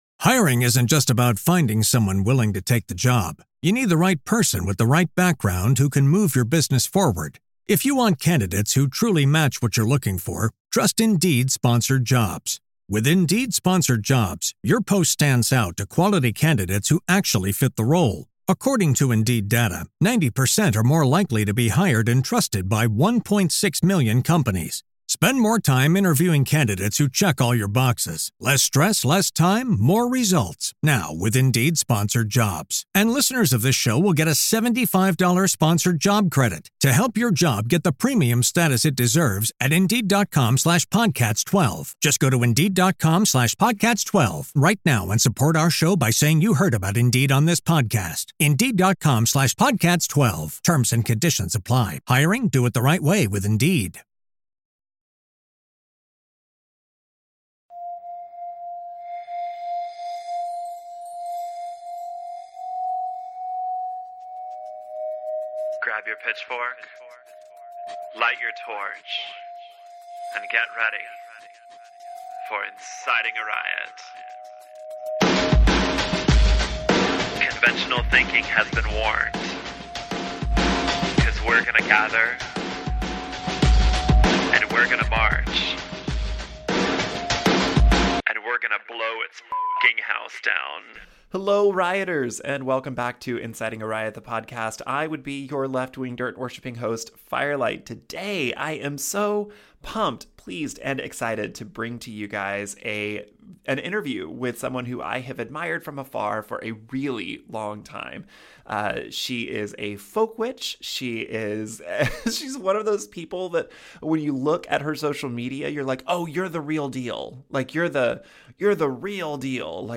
Episode 167 is an interview with folklorist